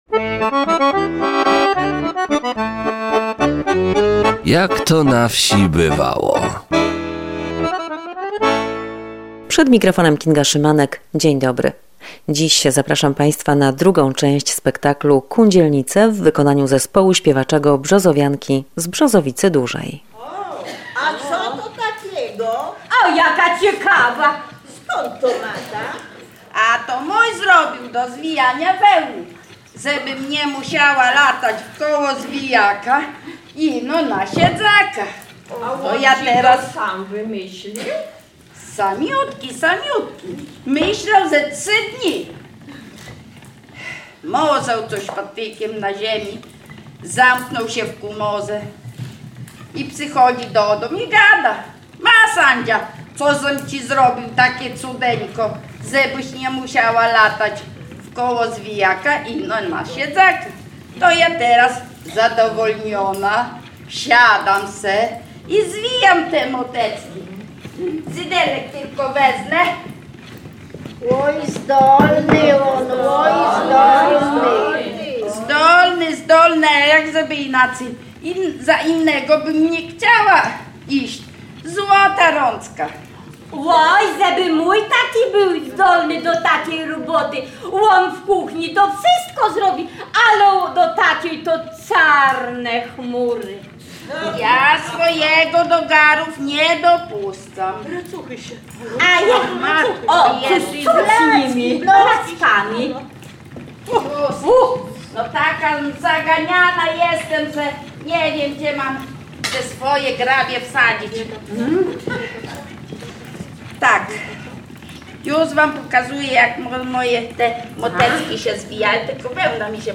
W programie cześć druga widowiska obrzędowego „Kundzielnice" w wykonaniu zespołu Brzozowianki z Brzozowicy Dużej.